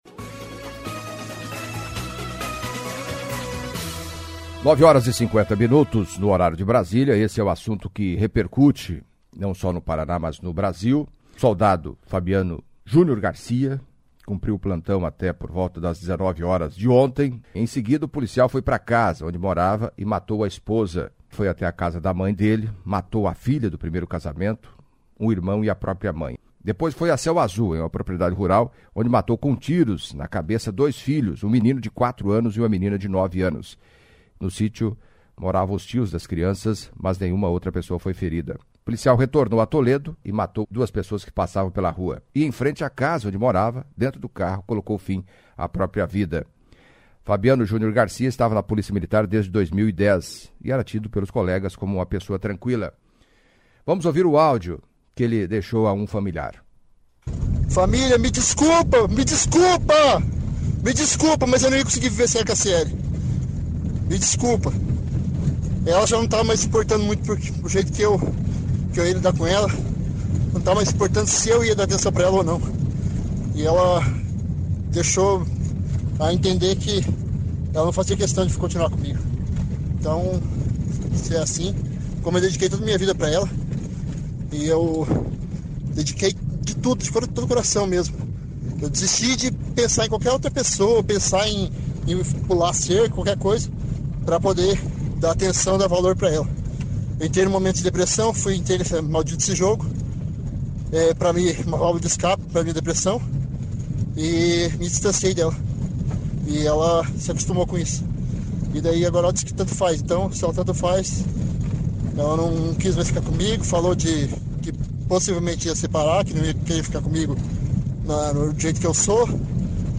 Em entrevista à CBN Cascavel nesta sexta-feira (15) a psicóloga